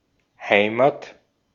Ääntäminen
Synonyymit pays nation Ääntäminen France: IPA: [pa.tʁi] Haettu sana löytyi näillä lähdekielillä: ranska Käännös Ääninäyte Substantiivit 1.